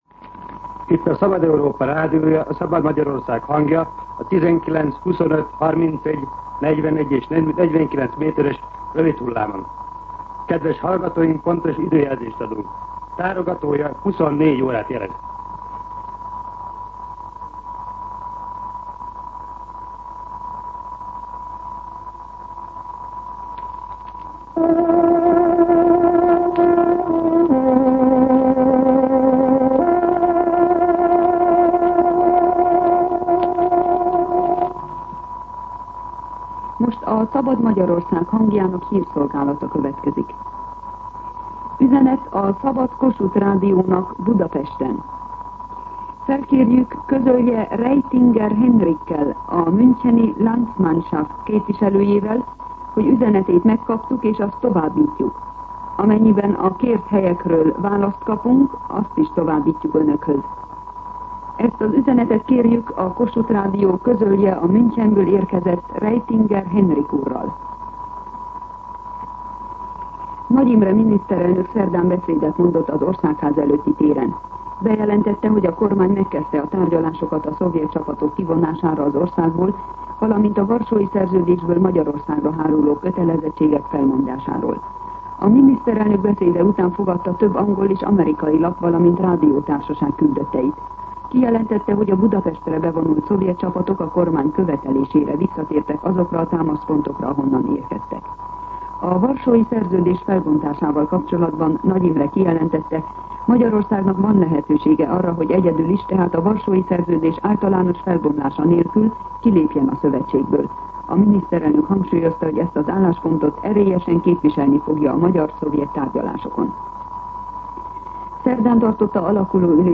24:00 óra. Hírszolgálat